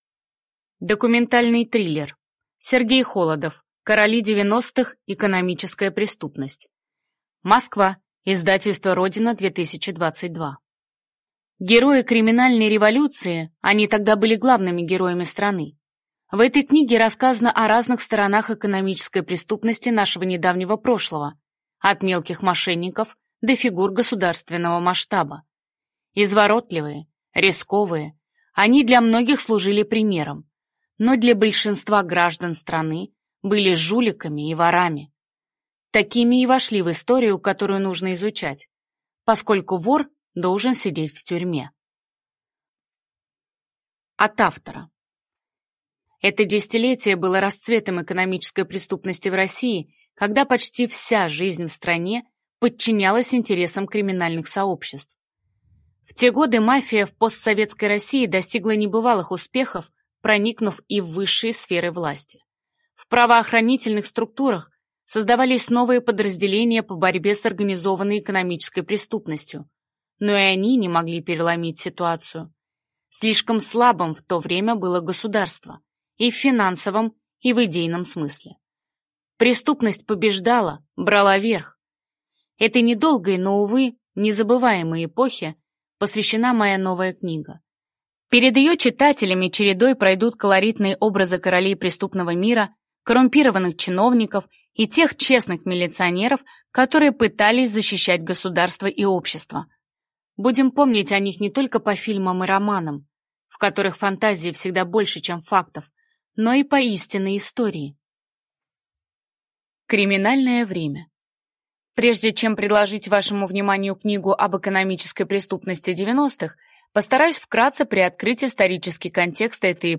Аудиокнига Короли 90-х. Экономическая преступность | Библиотека аудиокниг